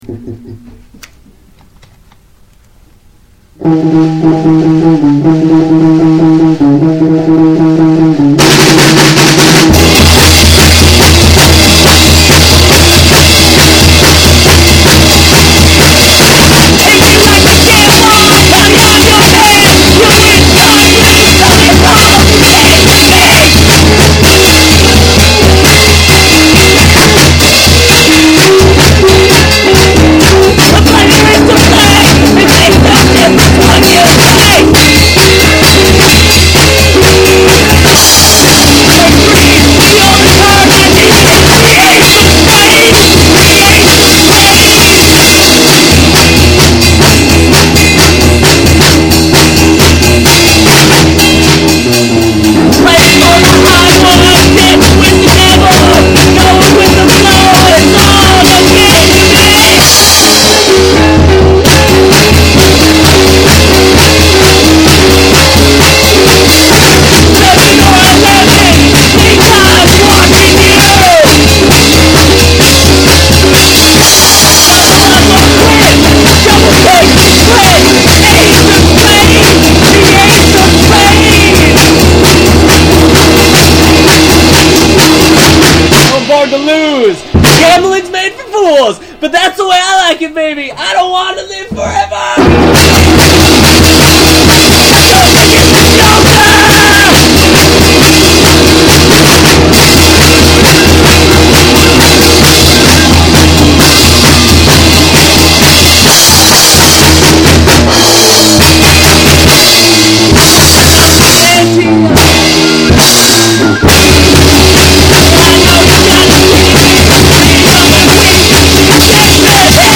full of awesome covers and fourtrack excellence.
for fans of loud and/or fast-paced songs.